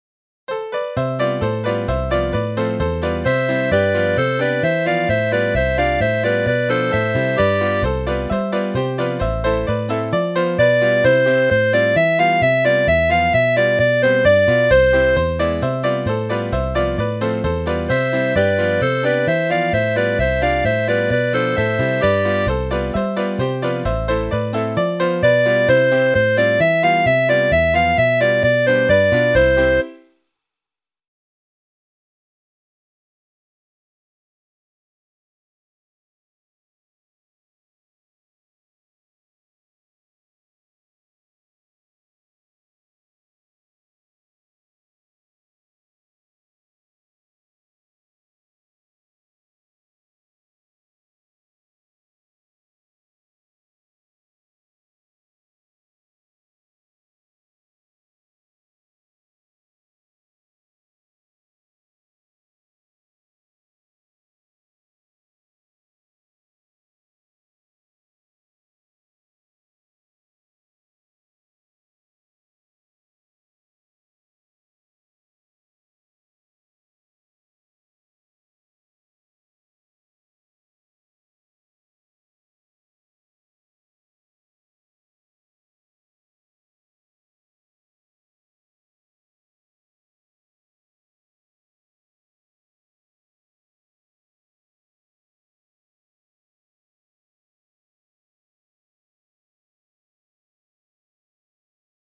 Les airs de gavotte appartiennent à deux espèces:
Le ton simple est réservé à la première gavotte de la suite tripartite, d'où son autre nom de "ton kentañ" (premier ton), et se compose de deux phrases de huit temps dont chacune est dite par le premier chanteur puis répétée par le second.